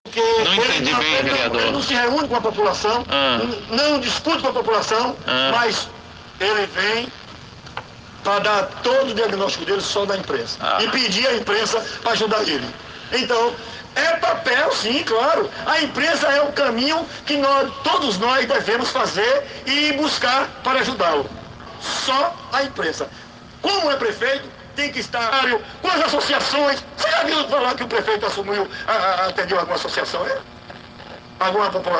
Fazendo uso de artigos da Constituição como sempre faz, o vereador Edvaldo Lima (MDB), soltou a pérola no Programa de Olho na Cidade da Rádio Sociedade News FM de Feira de Santana, nesta sexta-feira (20). Edvaldo Lima disse que quem bota o prefeito para trabalhar é a Câmara.